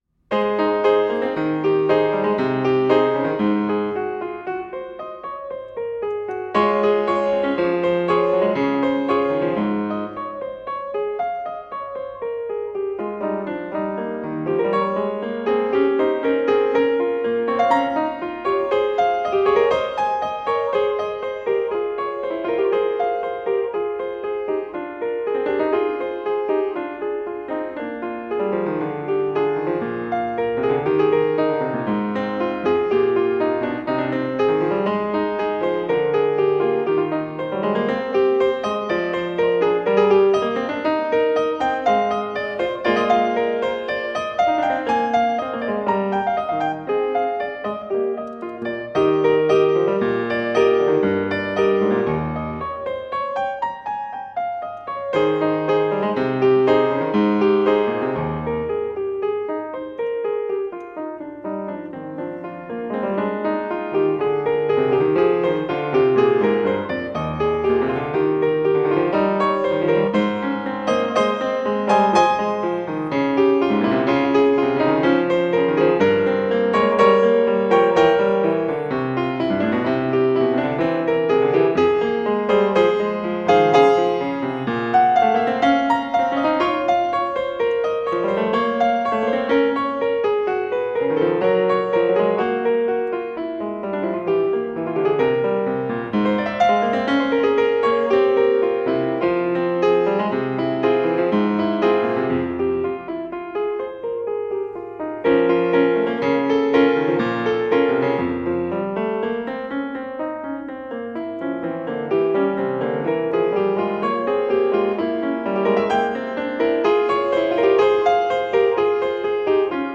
Classical Bach, Johann Sebastian Prelude and Fugue no.17 in G minor BWV886 from 48 Preludes and Fugues Book 2 Piano version
Piano  (View more Advanced Piano Music)
Classical (View more Classical Piano Music)